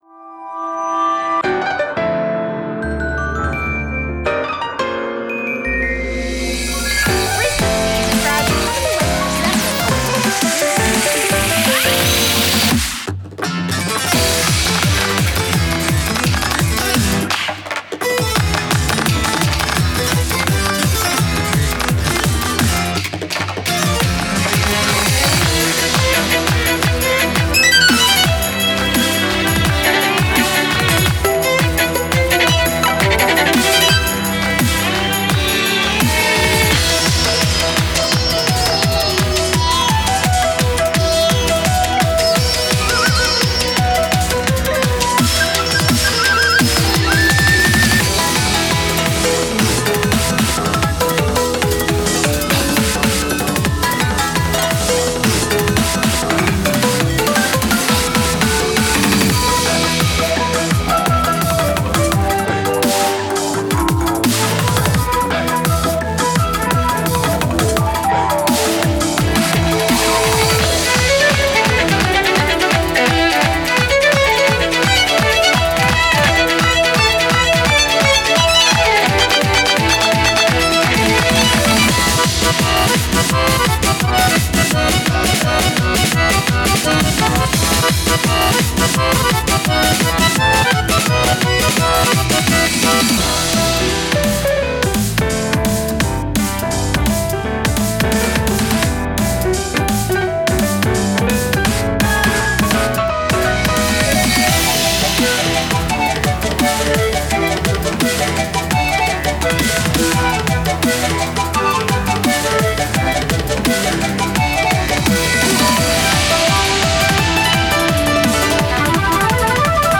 BPM170